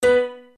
m_match_piano.ogg